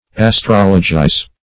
Search Result for " astrologize" : The Collaborative International Dictionary of English v.0.48: Astrologize \As*trol"o*gize\, v. t. & i. To apply astrology to; to study or practice astrology.